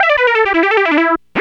Synth 20.wav